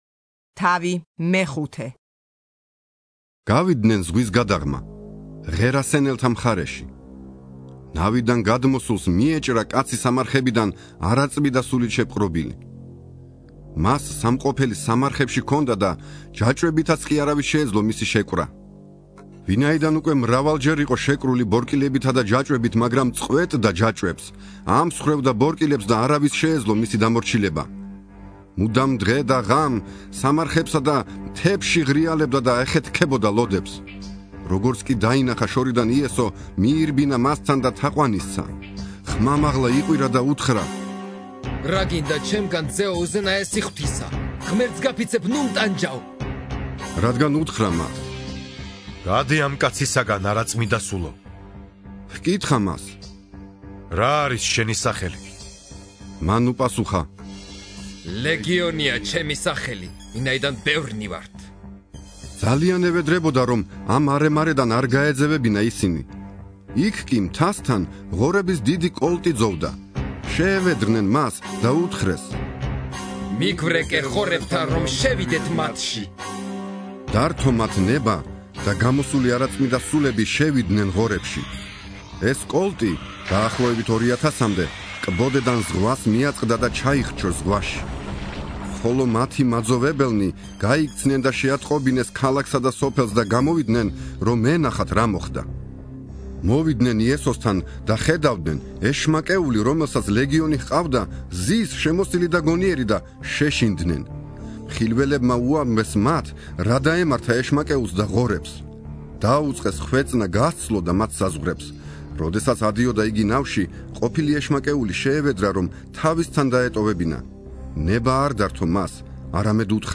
(41) ინსცენირებული ახალი აღთქმა - მარკოზის სახარება